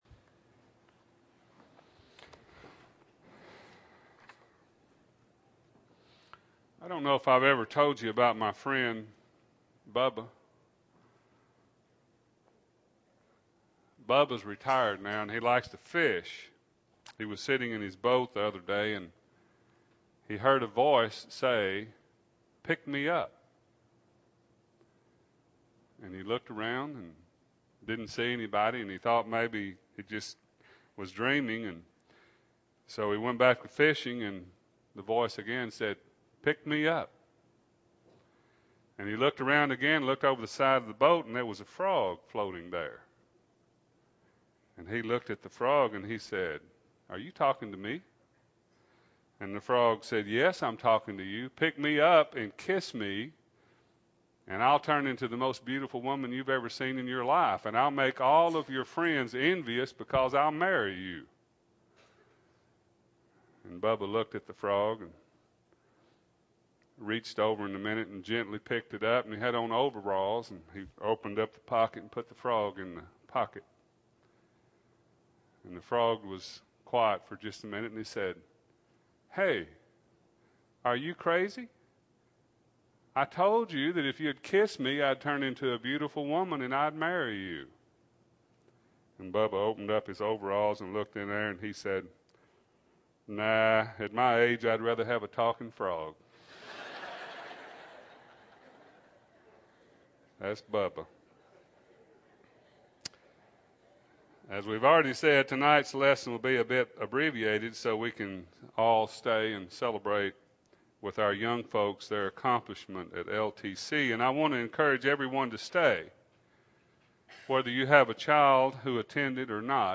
2008-03-30 – Sunday PM Sermon – Bible Lesson Recording